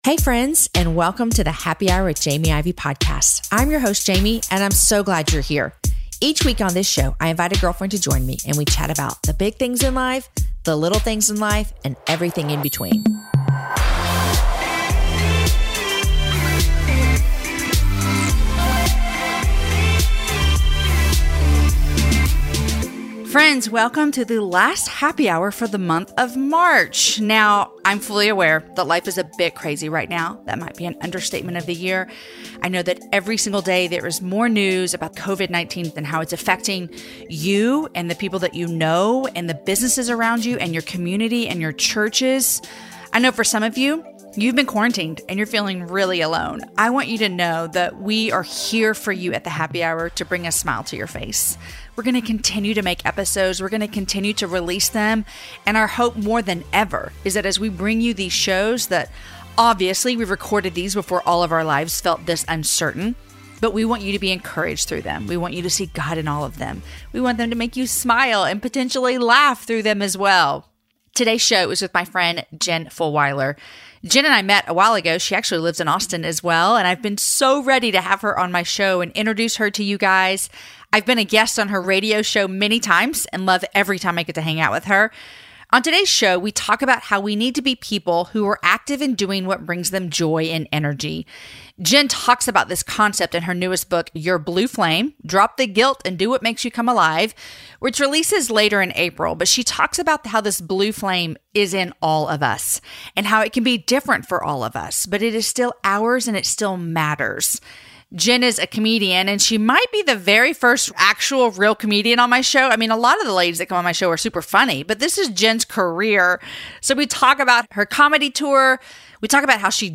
As a team, we have decided to air the six conversations that were recorded prior to May 20th.